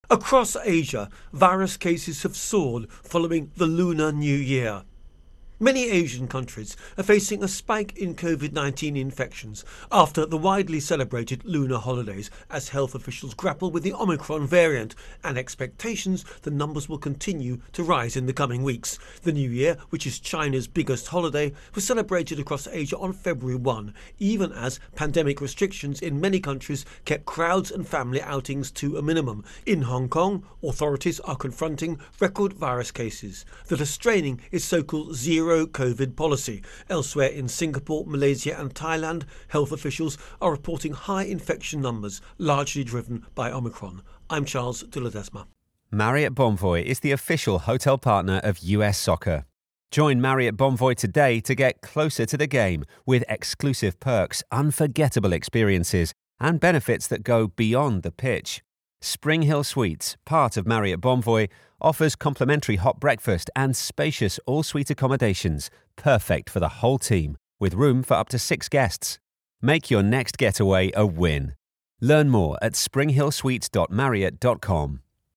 Virus Outbreak-Asia Intro and Voicer